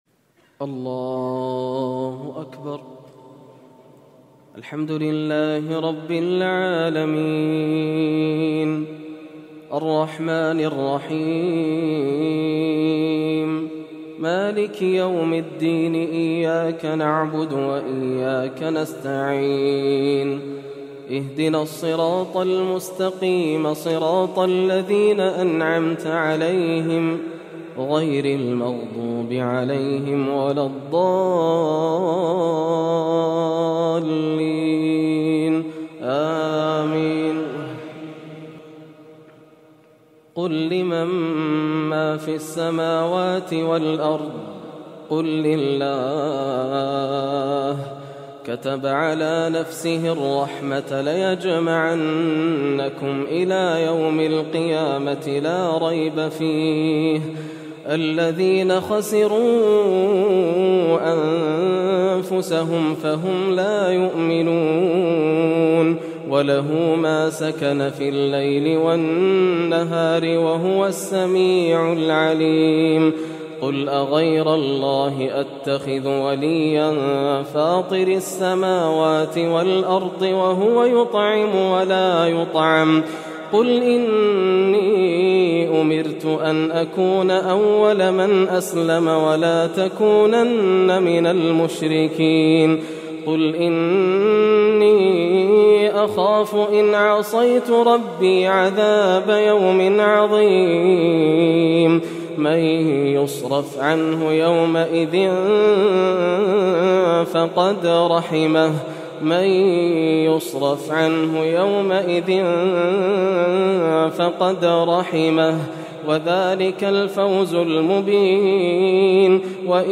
صلاة التراويح من سورة الأنعام للشيخ ياسر الدوسري | ليلة ١٠ رمضان ١٤٣١هـ > رمضان 1431هـ > مزامير الفرقان > المزيد - تلاوات الحرمين